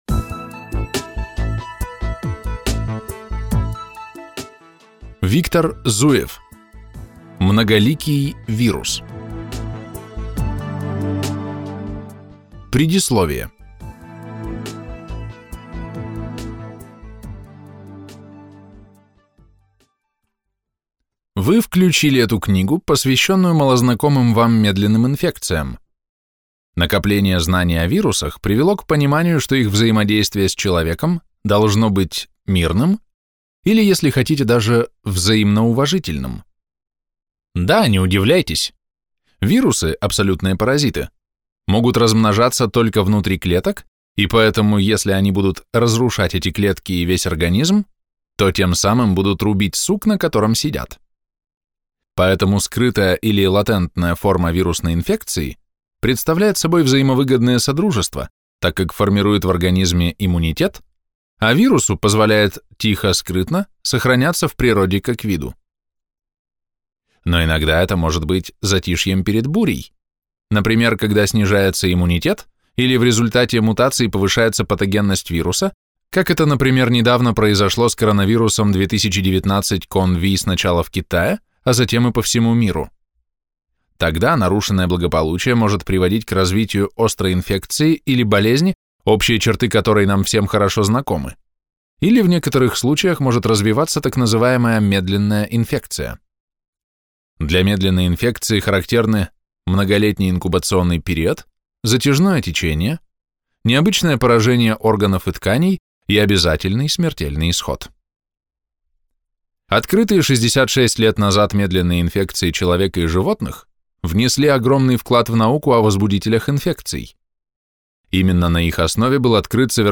Аудиокнига Многоликий вирус | Библиотека аудиокниг